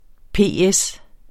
Udtale [ ˈpeˀˈεs ]